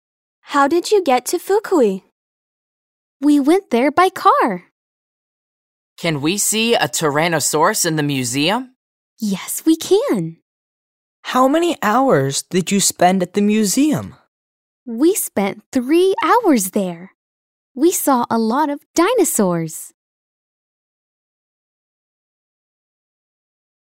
2020年5月13日(水) 英語科　教科書予習用　リスニング教材について